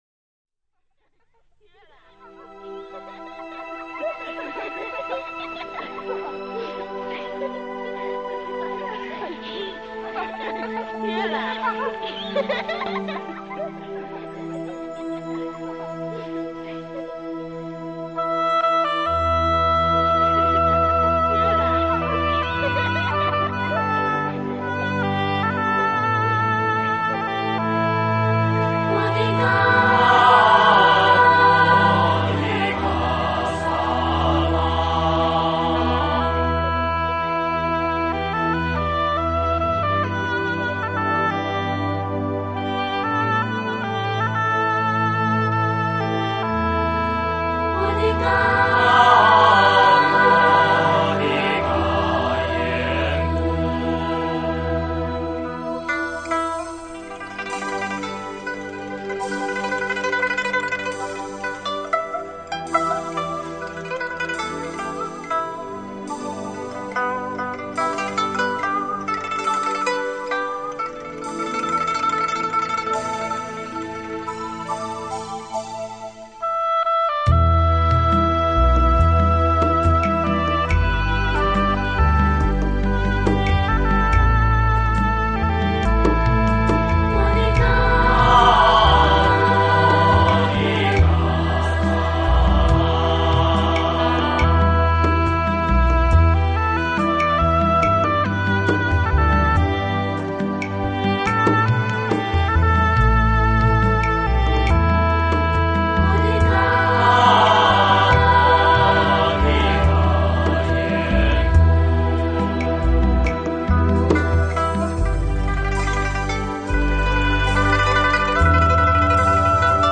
遠在大漠的哈薩克人，則吹起了牧笛、彈起了冬不拉（哈薩克最流行的彈奏樂器），